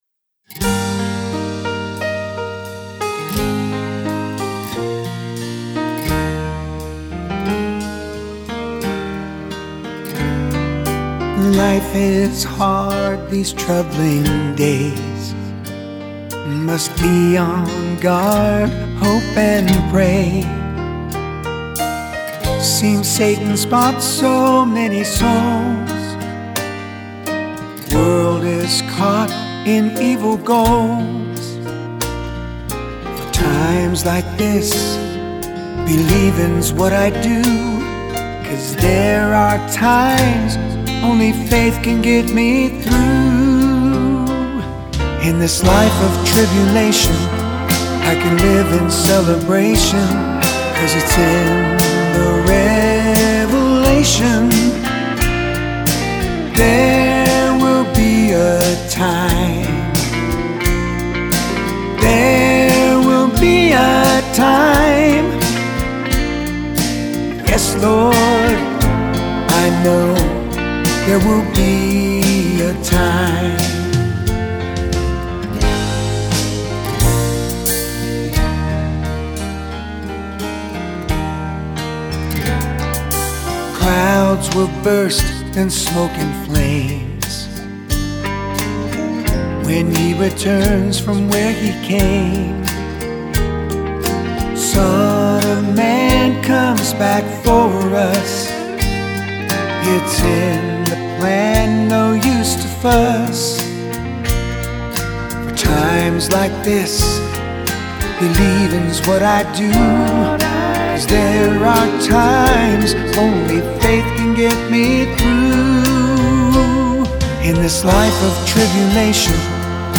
Complete Song